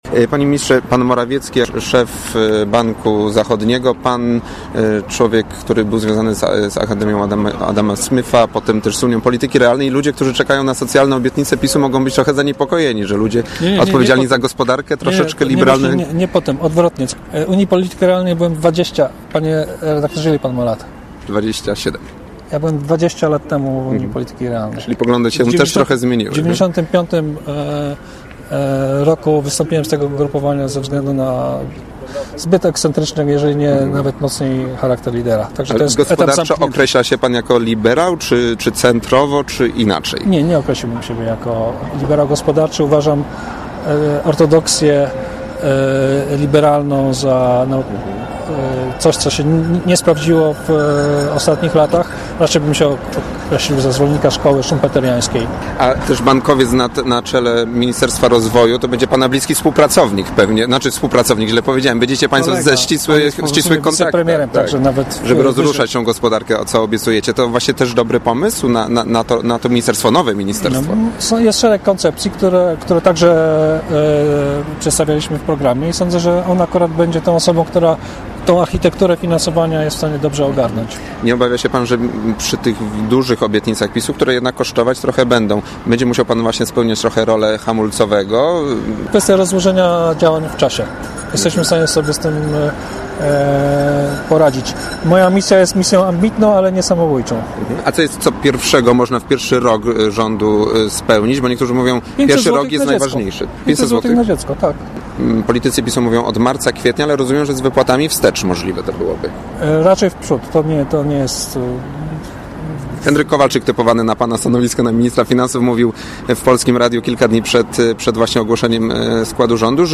Przyszły minister finansów - Paweł Szałamacha z PiS - spotkał się w poniedziałek z dziennikarzami na Placu Wolności w Poznaniu.